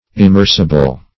Search Result for " immersible" : The Collaborative International Dictionary of English v.0.48: Immersible \Im*mers"i*ble\, a. [From Immerse .]